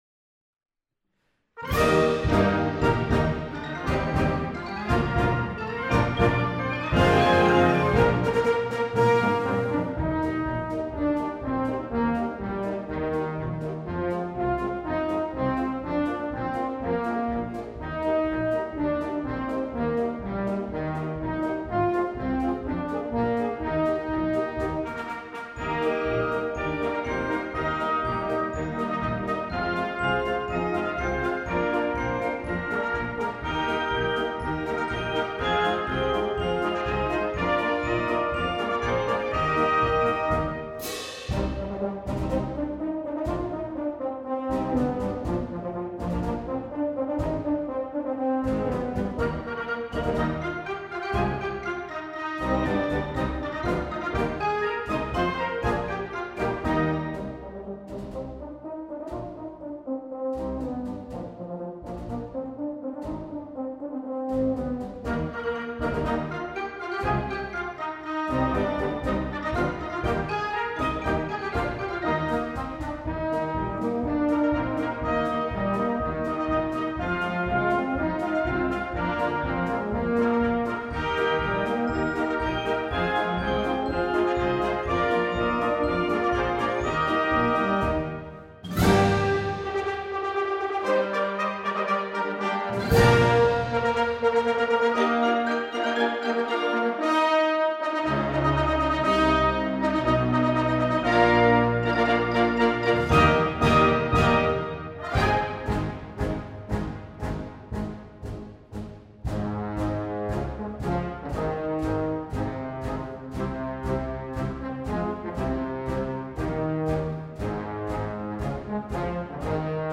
Blasorchester
Unbeschwerter, fröhlicher Marsch